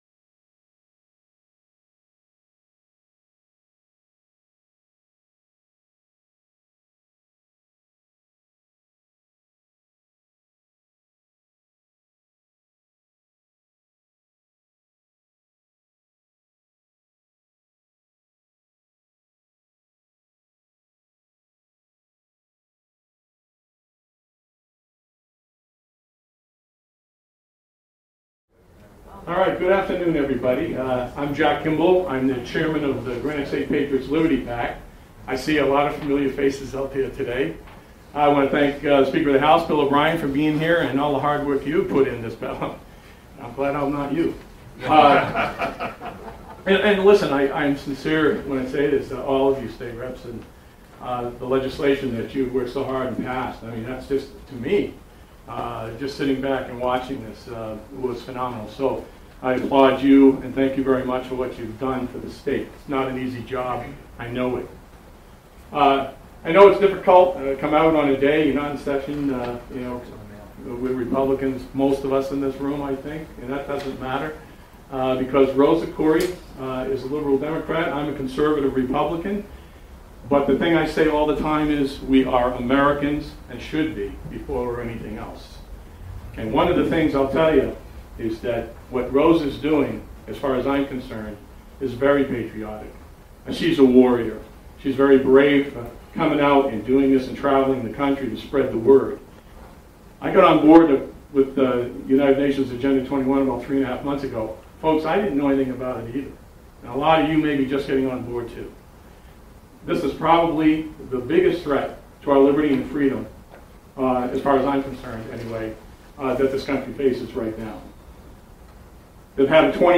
It took place in the Legislative Office Building just behind the Capital in Concord on June 25, 2012.